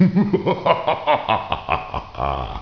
Mortal Kombat Laugh 2
laugh.wav